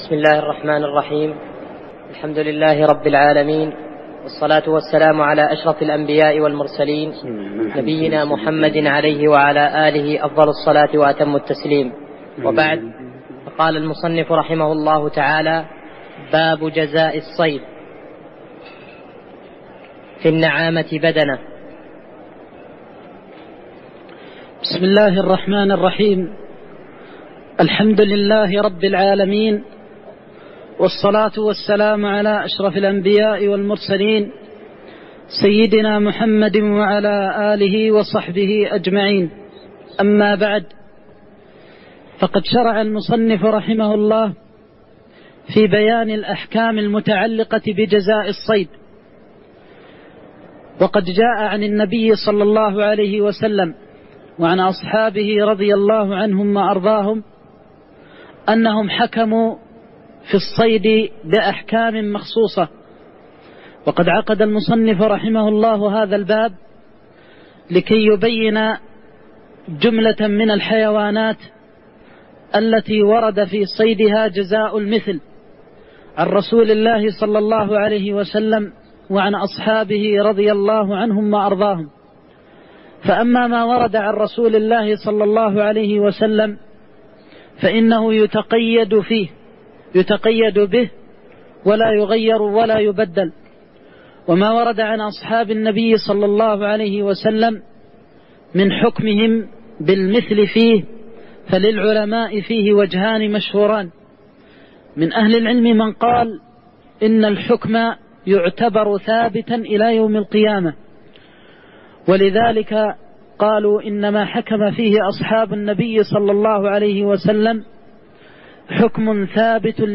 تاريخ النشر ٢٦ شوال ١٤١٧ هـ المكان: المسجد النبوي الشيخ